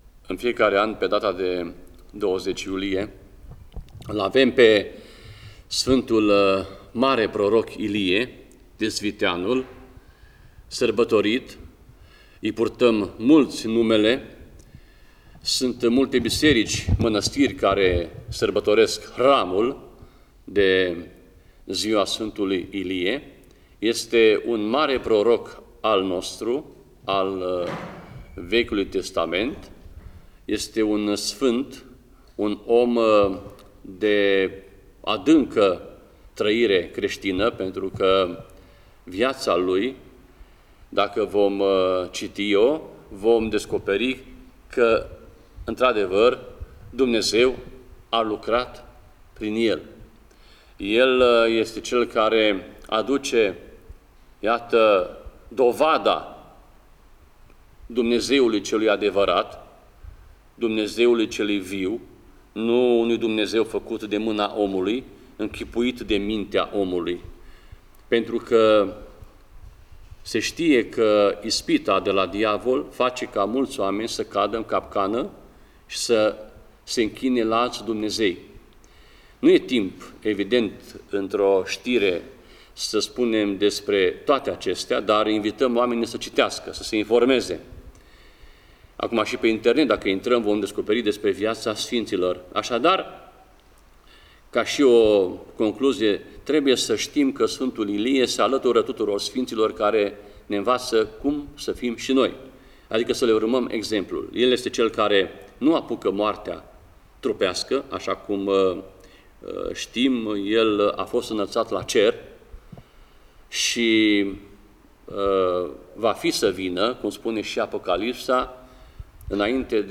Preot